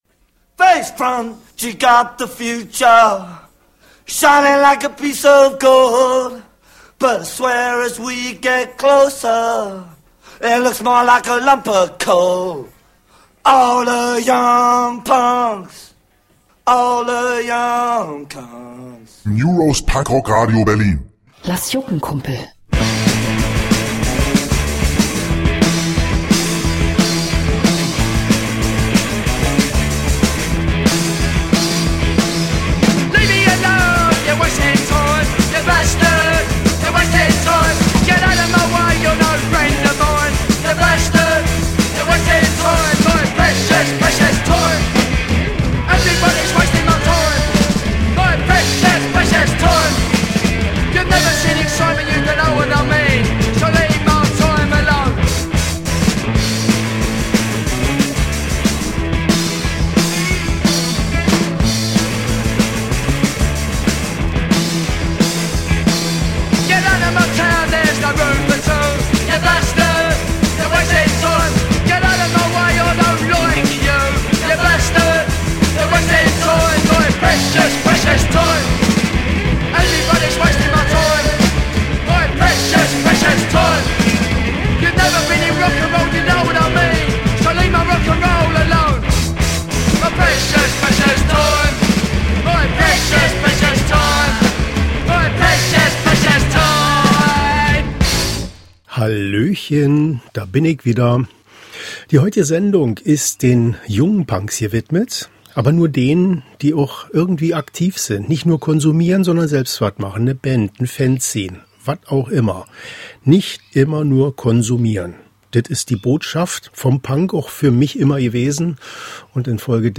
Nun gibt’s doch mal wieder einige Neuigkeiten. Dazu Konzerthinweise, Hörerwünsche, alte Schätze…eben das ganze Programm!